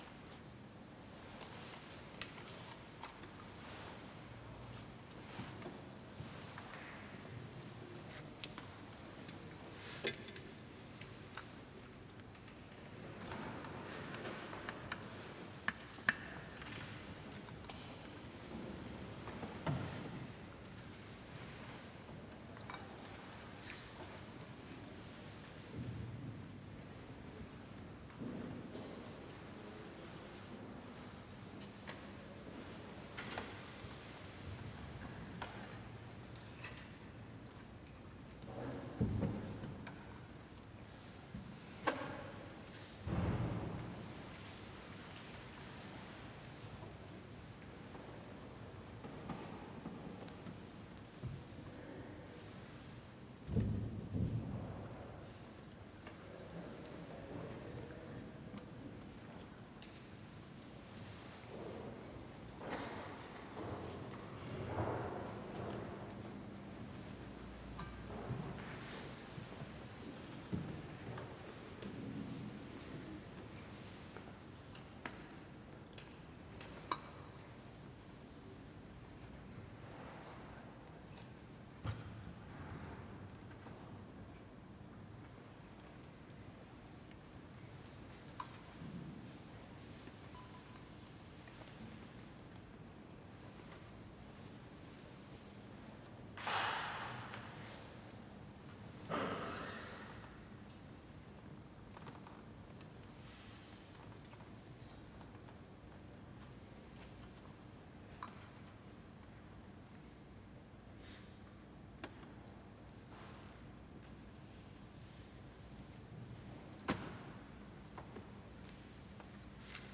Quartet improvisation
laptop
trombone
snare & cymbals
rice and dish.